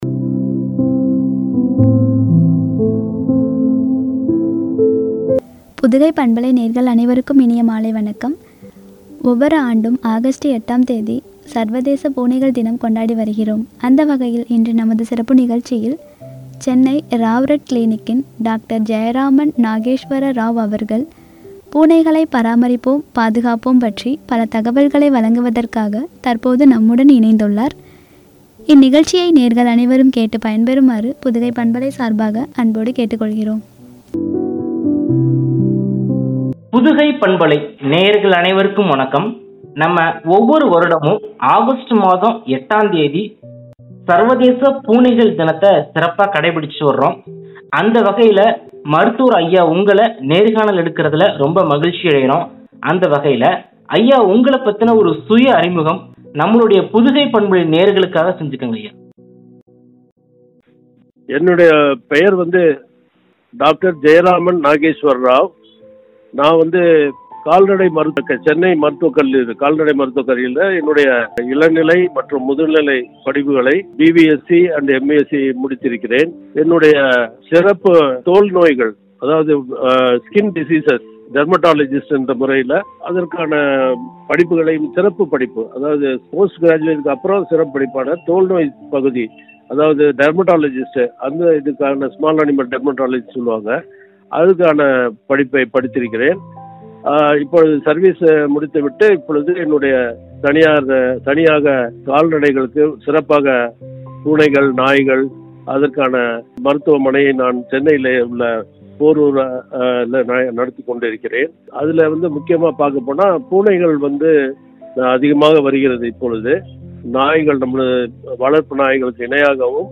பாதுகாப்பும்” குறித்து வழங்கிய உரையாடல்.